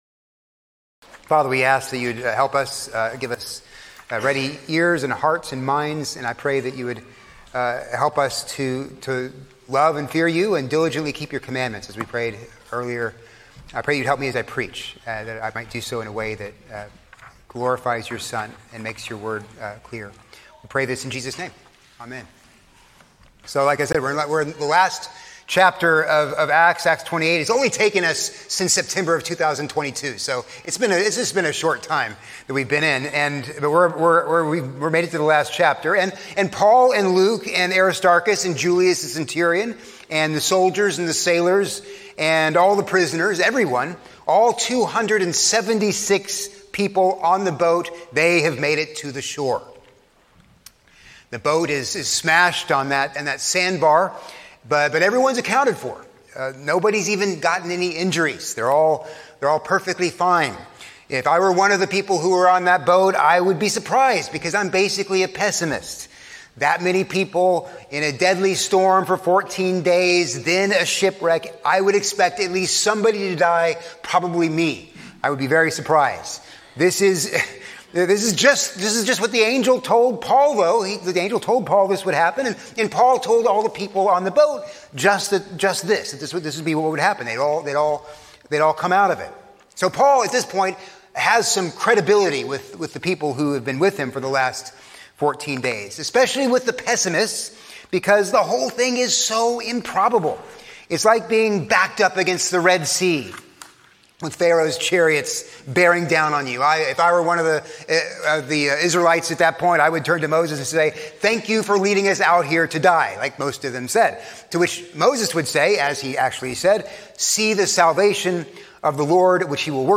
A sermon on Acts 28:1-10